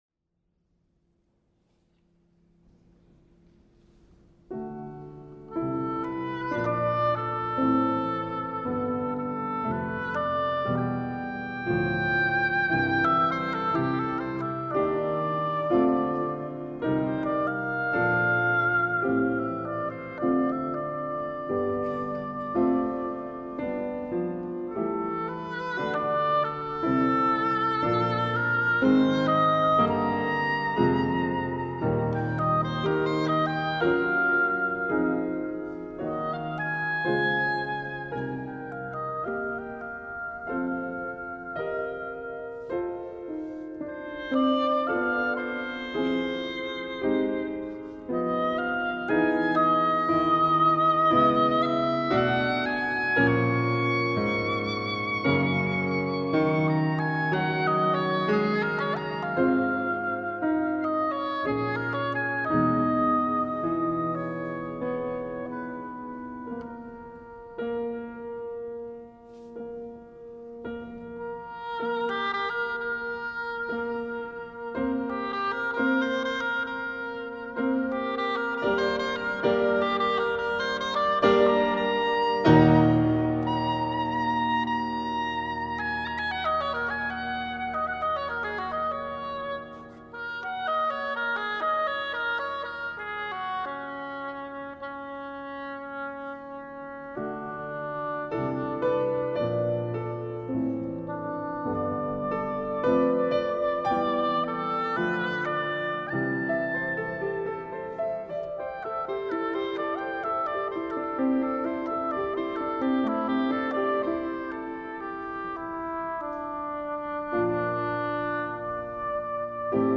23 NOVEMBRE 2018 – 18° Concerto di Musica Classica
Nino Rota: Elegia per oboe e pianoforte
Come da tradizione l’Associazione “Ed allora sarà sempre festa per te” ha offerto alla cittadinanza il “Concerto di Natale”, nella prestigiosa location della SALA DEI GIGANTI a Palazzo Liviano dell’Università degli Studi di Padova.